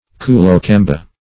Search Result for " koolokamba" : The Collaborative International Dictionary of English v.0.48: Koolokamba \Koo`lo*kam"ba\, n. (Zool.) A west African anthropoid ape ( Troglodytes koolokamba , or Troglodytes Aubryi ), allied to the chimpanzee and gorilla, and, in some respects, intermediate between them.